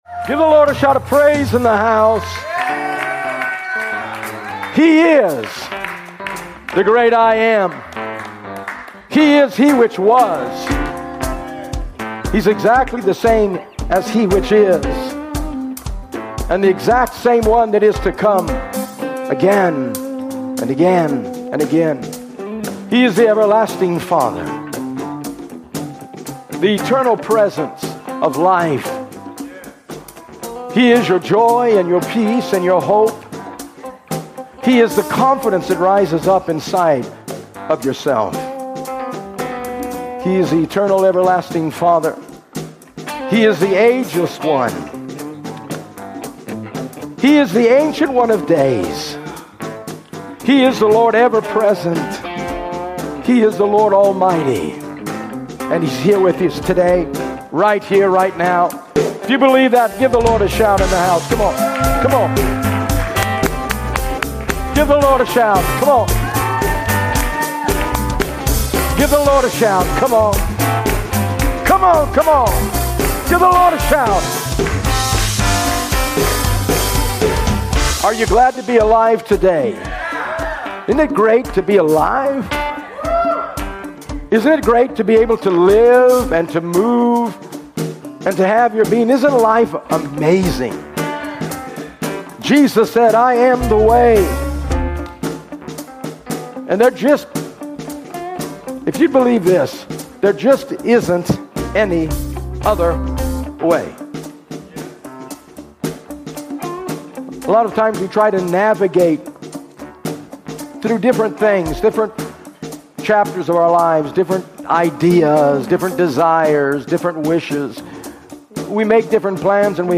Sermon: Full Service: